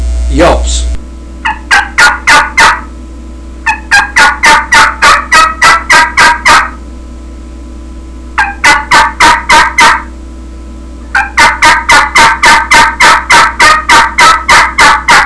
• Makes deep, raspy yelps, cackles, clucks, and cutts at all volume levels.
qbgrworldchampyelps15.wav